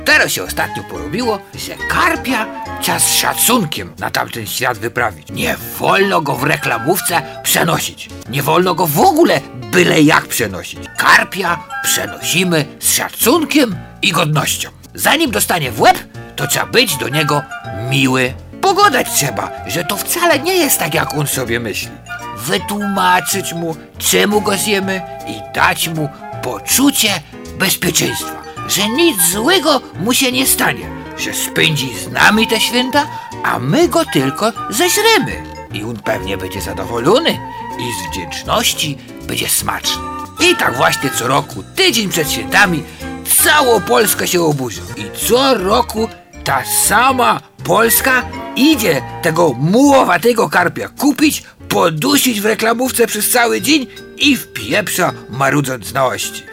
Felietony humorystyczne Usta Szczepona, które mogliście usłyszeć na antenie Radia Żnin FM zostały nominowany do Grand PiK 2014 w plebiscycie International Artist Radio Form Competition. W konkursie bierze udział odcinek pod tytułem karp, który opowiada o corocznej walce przeciwników i zwolenników zabijania karpi na wigilijny stół.